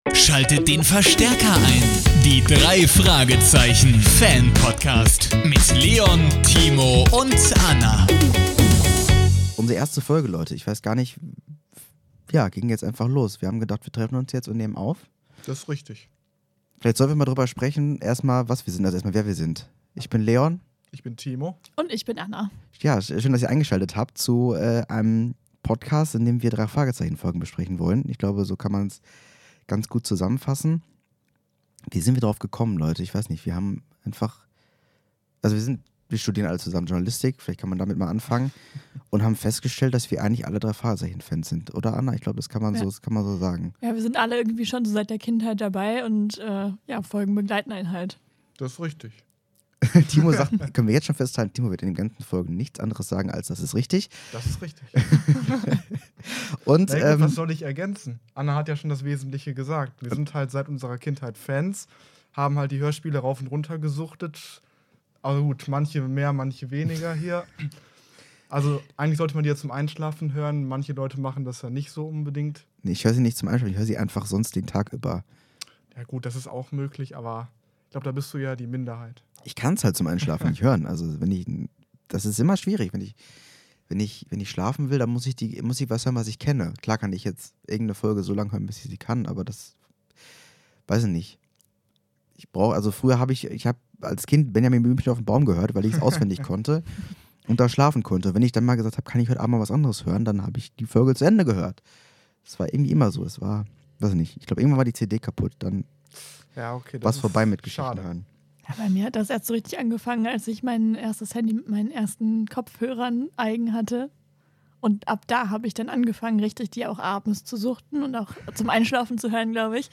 Hier besprechen und diskutieren sie regelmäßig die Hörspiel-Folgen bzw. Buch-Geschichten. Los geht’s direkt mit der aktuellsten Folge, Nr. 237 „Und der rote Büffel”.